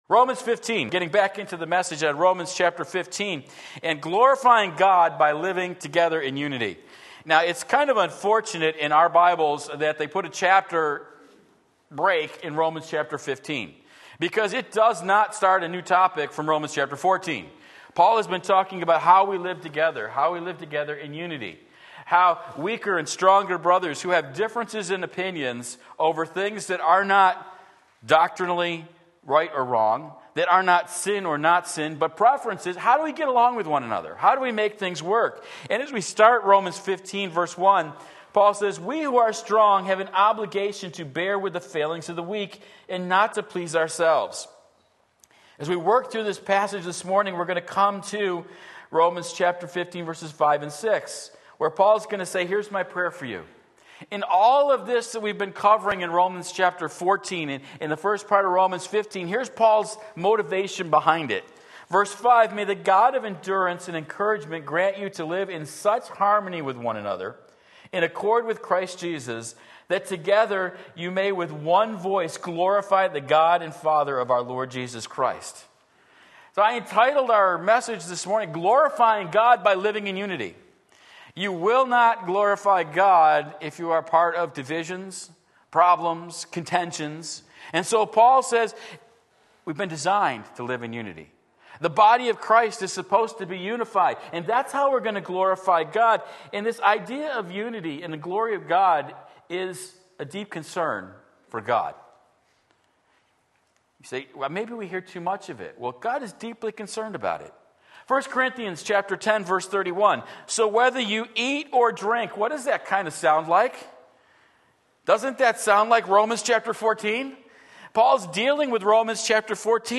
Sermon Link
Glorify God by Living in Unity Romans 15:1-6 Sunday Morning Service